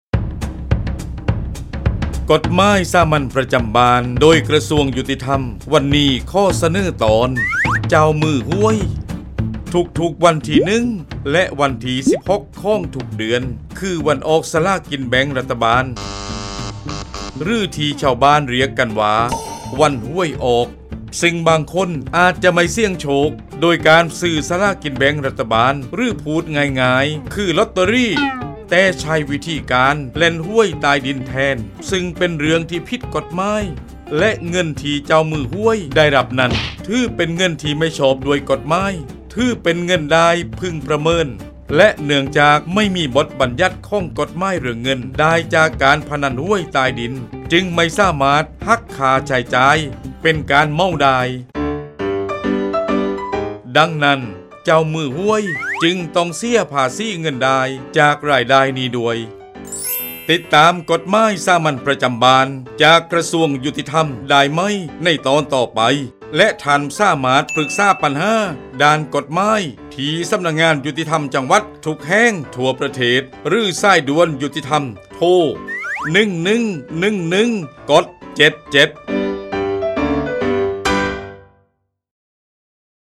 กฎหมายสามัญประจำบ้าน ฉบับภาษาท้องถิ่น ภาคใต้ ตอนเจ้ามือหวย
ลักษณะของสื่อ :   บรรยาย, คลิปเสียง